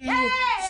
BWB 5 Chant J Cole (6).wav